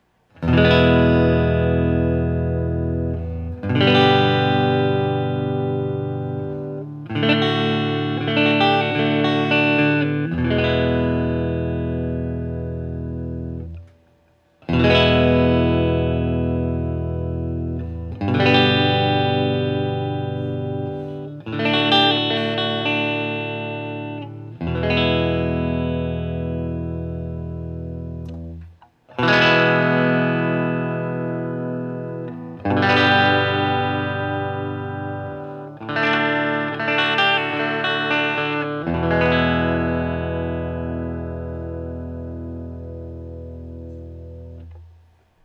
Every sound sample cycles from the neck pickup, to both, to the bridge pickup.
Open Chords #1
[/dropshadowbox]For these recordings I used my normal Axe-FX Ultra setup through the QSC K12 speaker recorded into my trusty Olympus LS-10.